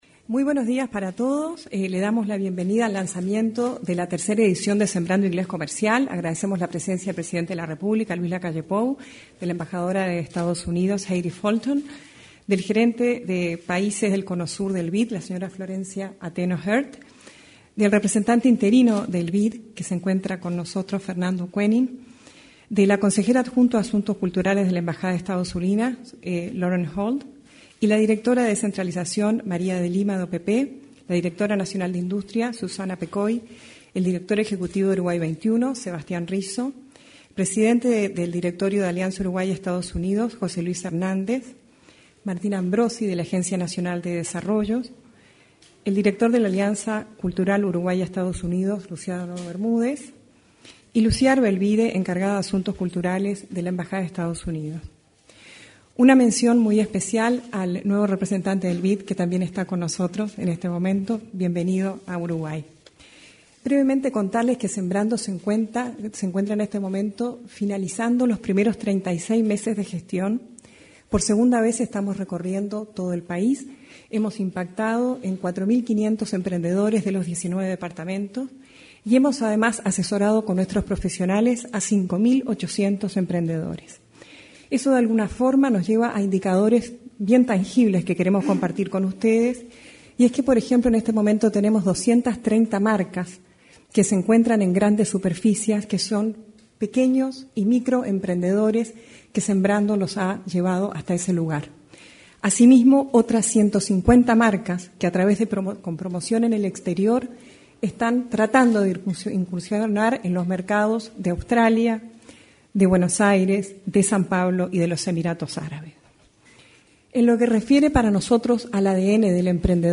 Este martes 28, se realizó en el salón de Actos de la Torre Ejecutiva, el Lanzamiento de curso Inglés Comercial 2023, del programa Sembrando.